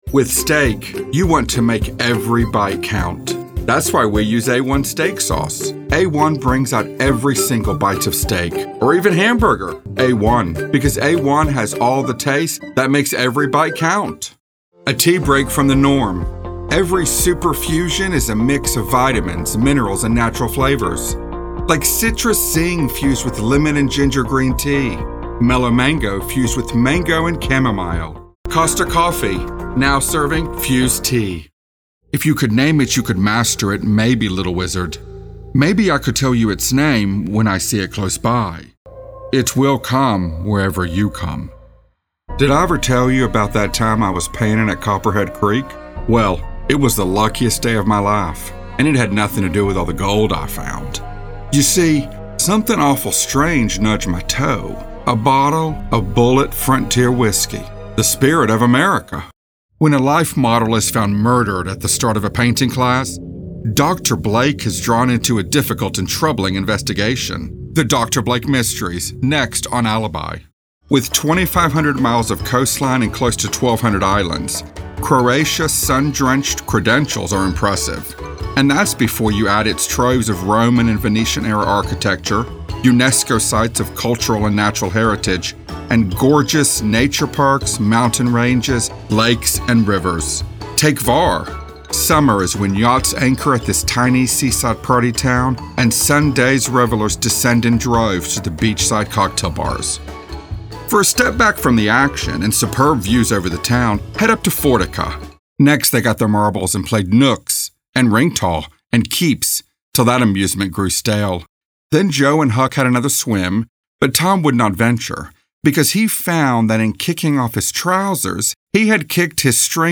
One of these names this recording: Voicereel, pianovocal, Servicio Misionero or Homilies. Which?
Voicereel